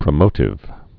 (prə-mōtĭv)